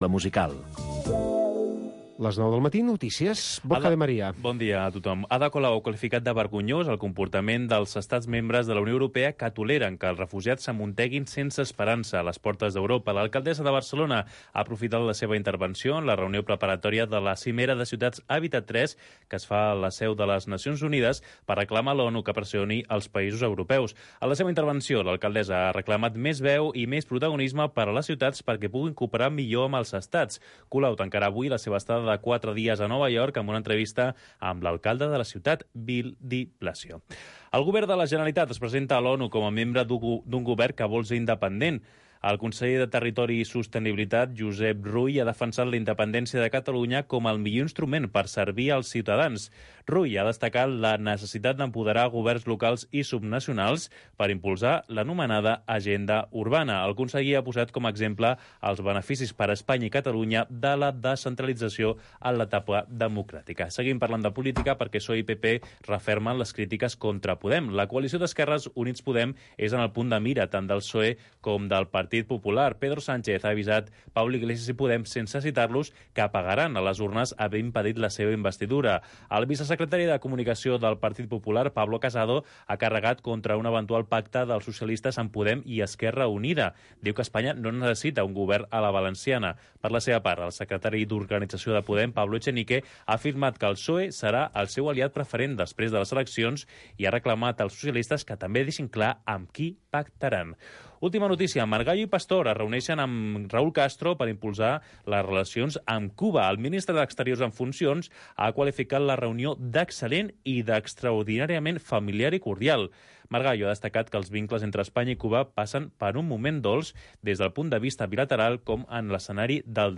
ens presenta un pacient que ens explica el seu cas en primera persona.